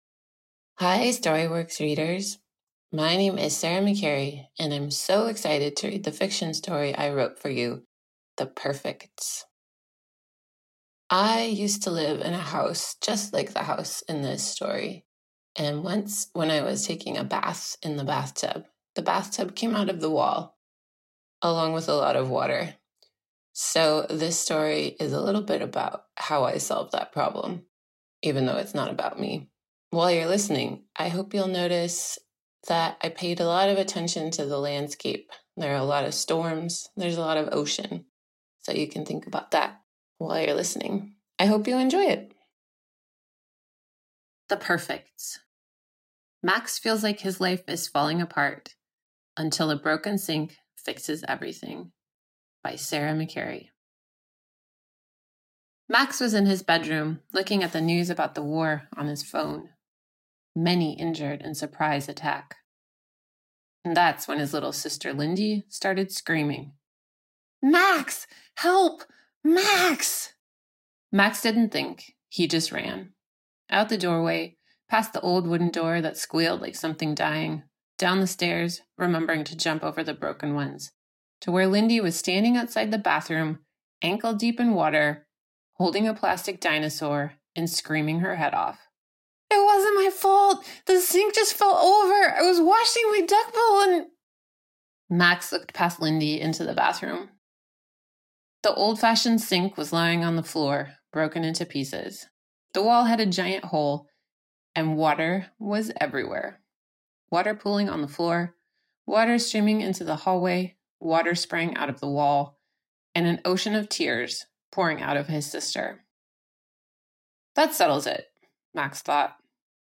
Author Read-Aloud